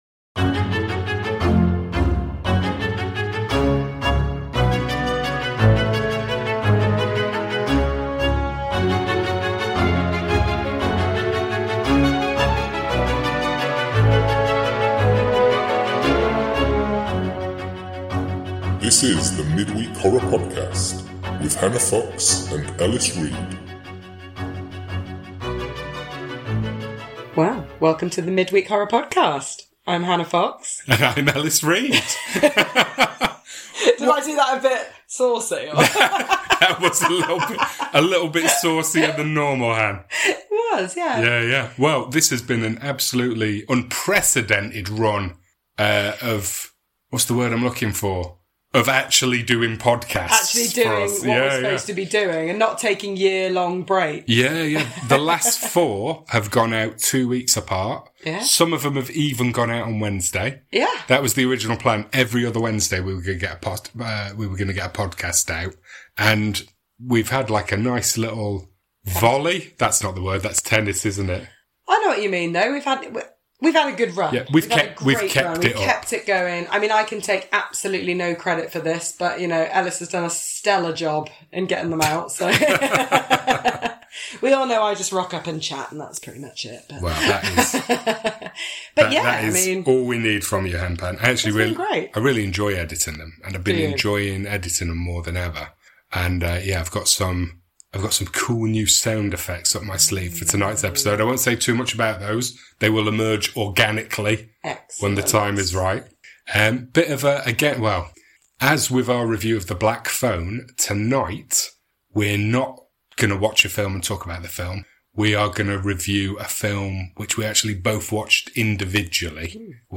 Welcome to our horror movie podcast!
In this episode, we cover the recent version of NOSFERATU by Robert Eggers. Also includes a funny quiz, some brand-new sound effects, and a surprisingly good impression of a much-loved muppet.